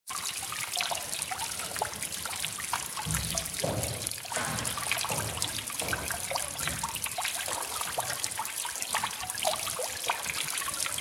El encaje arquitectónico es más fácilmente fotografiable cuando solieron prever el ambiente sonoro en el sitio: